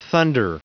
Prononciation du mot thunder en anglais (fichier audio)
Prononciation du mot : thunder